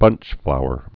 (bŭnchflouər)